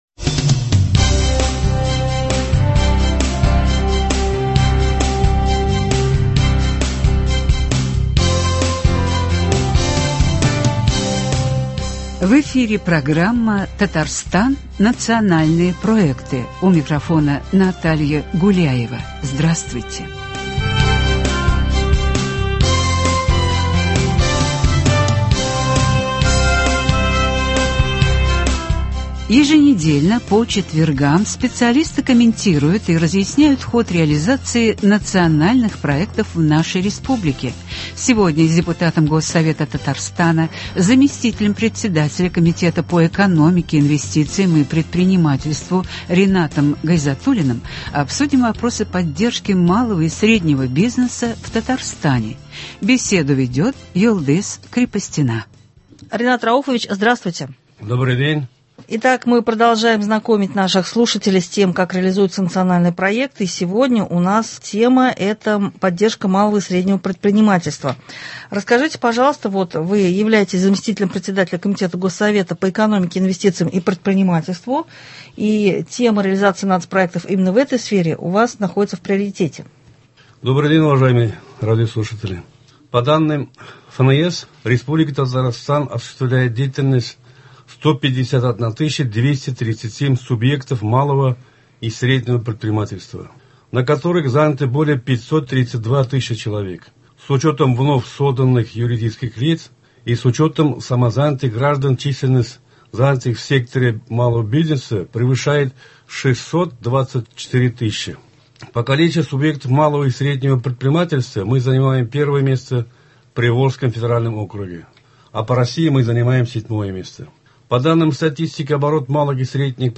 О поддержке бизнеса — беседа с депутатом Госсовета Татарстана, Заместителем председателя Комитета по экономике, инвестициям и предпринимательству